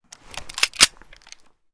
grach_unjam.ogg